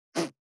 417,ジッパー,チャックの音,洋服関係音,ジー,バリバリ,カチャ,ガチャ,シュッ,パチン,ギィ,カリ,カシャ,スー,
ジッパー効果音洋服関係
ジッパー